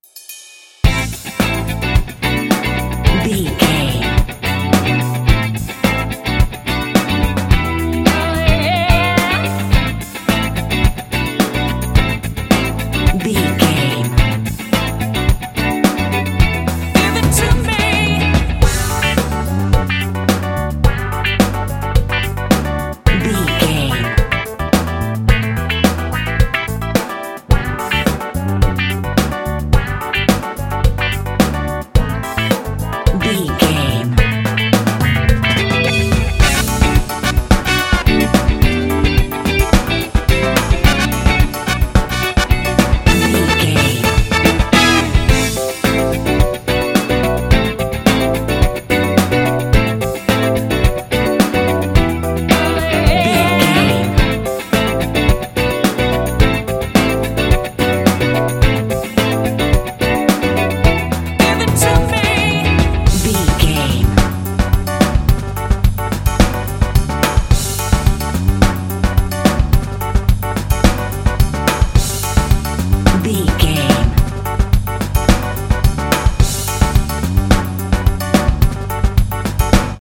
Ionian/Major
D
bright
groovy
funky
mellow
smooth
bass guitar
electric piano
horns
drums
vocals
electric guitar